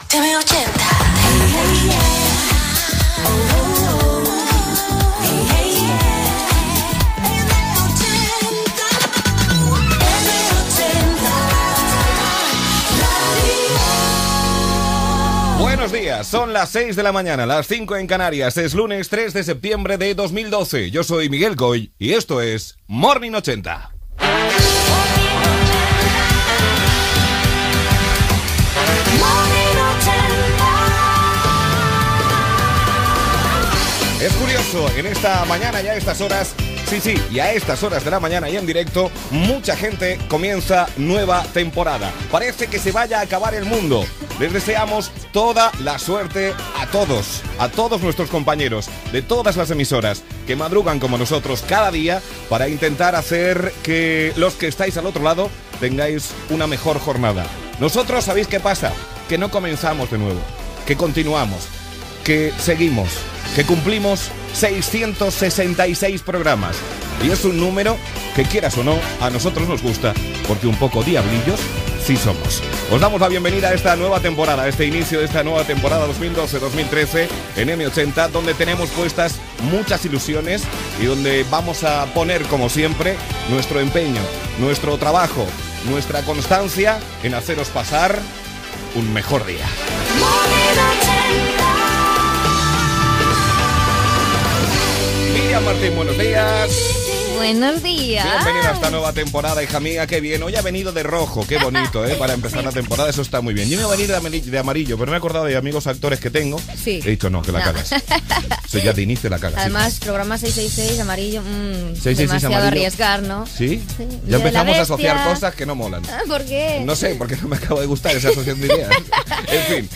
Indicatiu de la ràdio, hora, data, desig de bona temporada radiofònica en l'edició 666, presentació de l'equip
Entreteniment